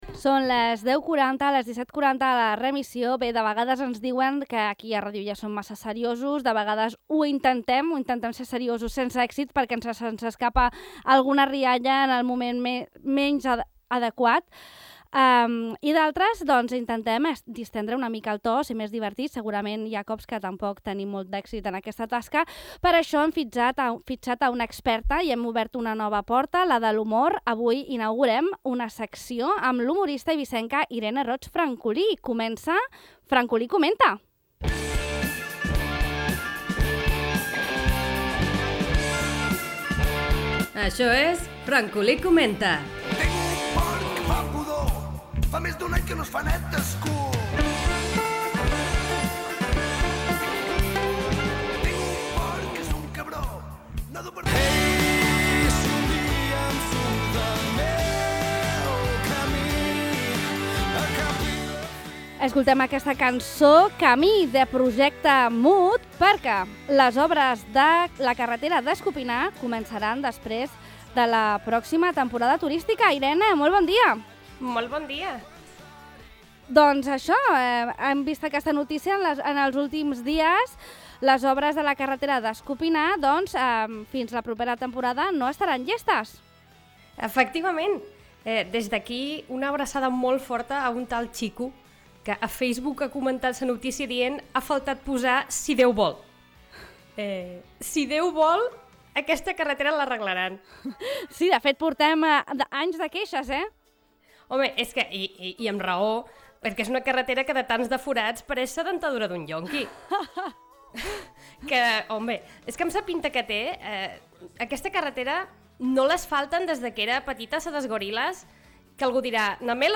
Nou espai de sàtira.